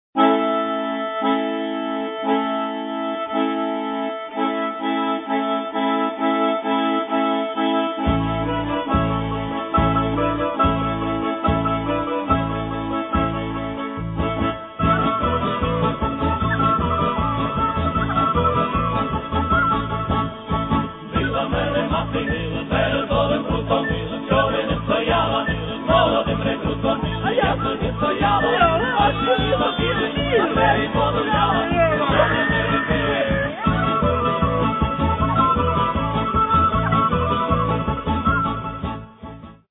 Каталог -> Народна -> Ансамблі народної музики
чи не самий життєрадісний фольклорний колектив